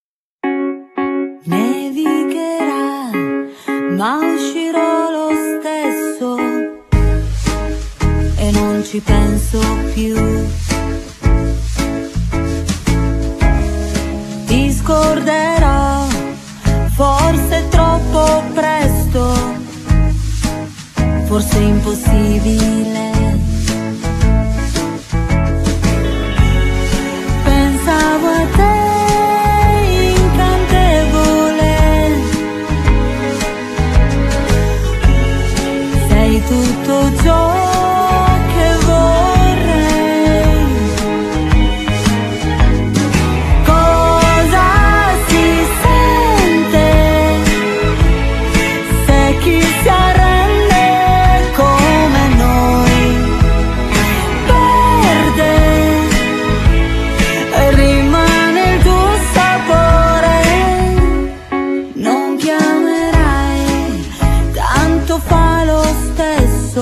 Genere : Pop
Un ritorno alle melodie degli anni 60
con strumenti vintage originali